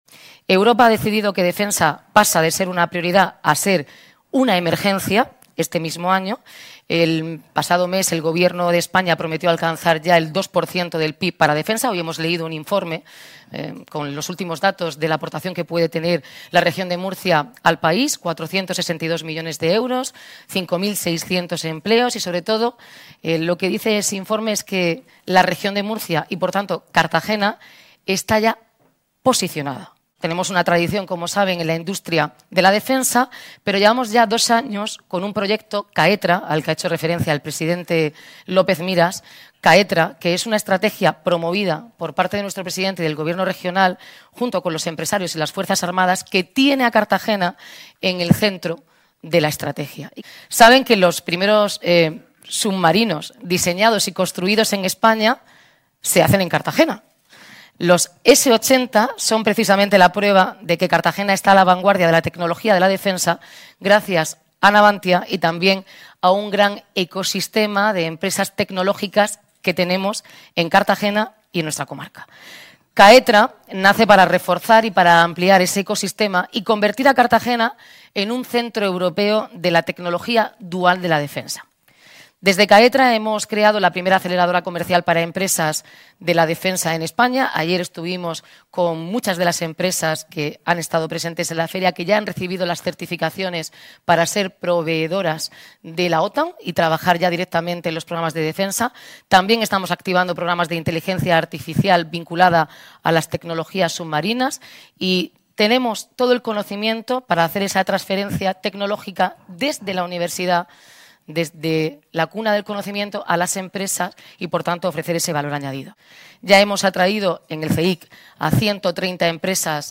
Así lo ha expuesto durante su intervención en el desayuno informativo organizado por Europa Press, donde ha sido presentada por el presidente de la Comunidad Autónoma, Fernando López Miras.